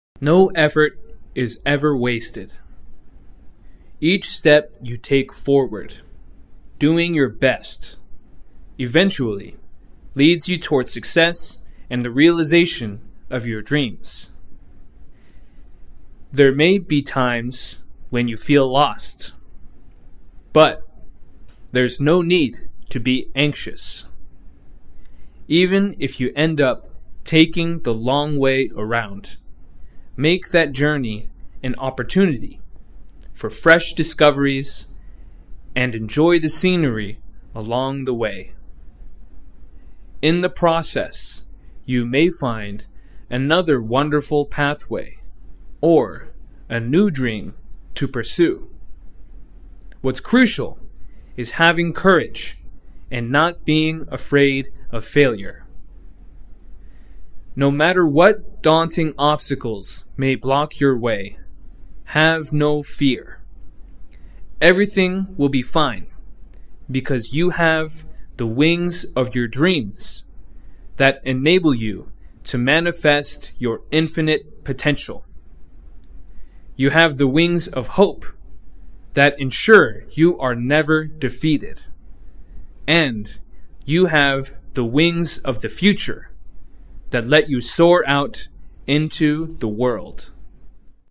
模範音声再生（スロー版） 模範音声再生（スロー版）
k_slow_english2025_model.mp3